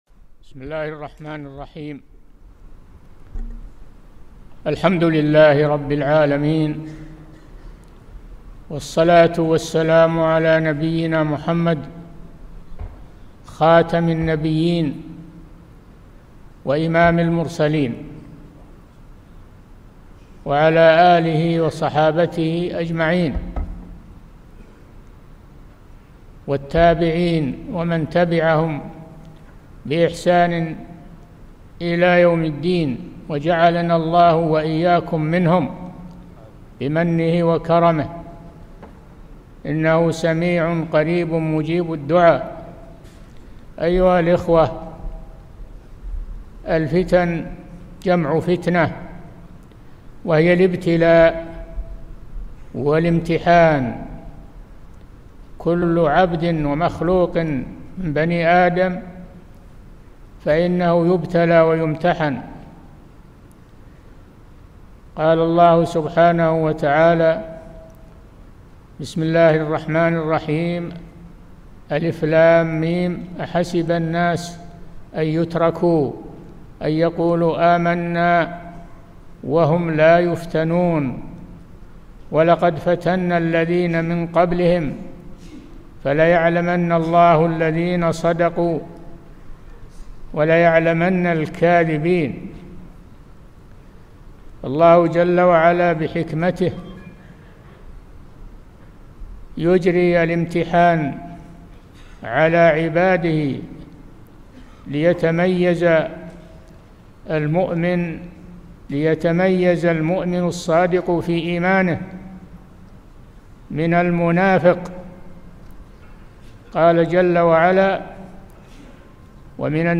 محاضرة - موقف المسلم من الفتن